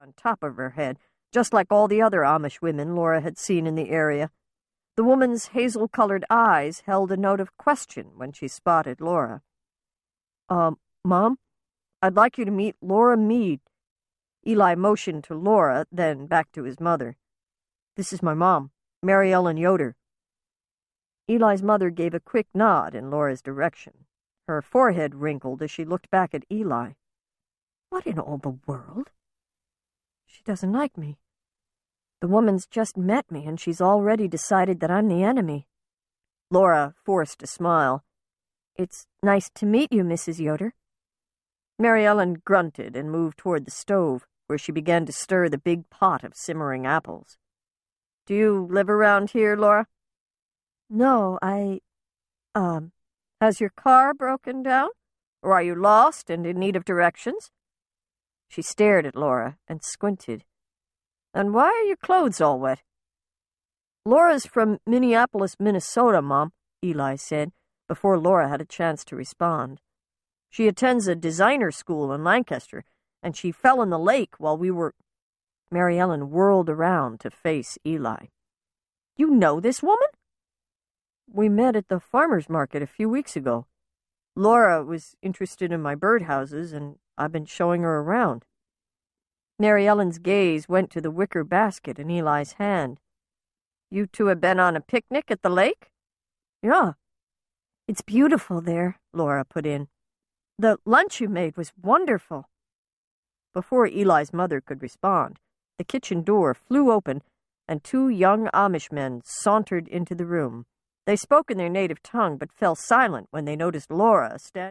Plain and Fancy (Brides of Lancaster County Series, Book #3) Audiobook
7.25 Hrs. – Unabridged